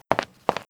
foot_steps_att_1.wav